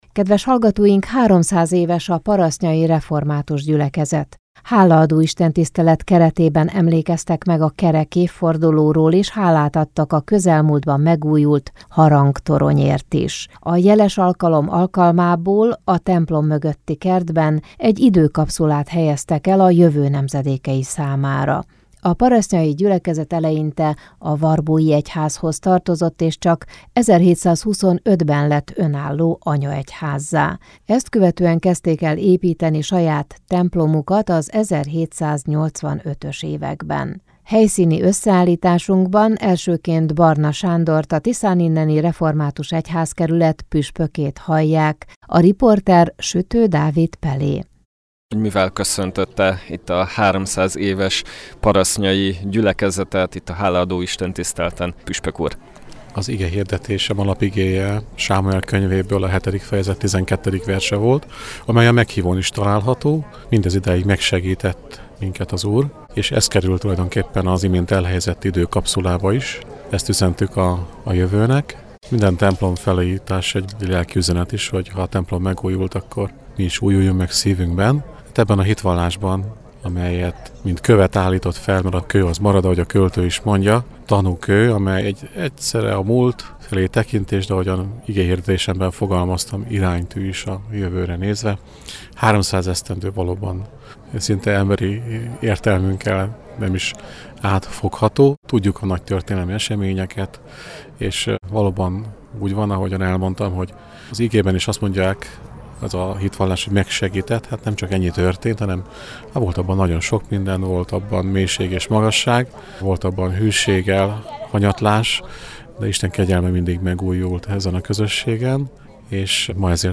Hálaadó istentisztelet keretében emlékeztek meg a kerek évfordulóról és hálát adtak a közelmúltban megújult harangtoronyért is. A jeles alkalom alkalmából a templom mögötti kertben egy időkapszulát helyeztek el a jövő nemzedékei számára.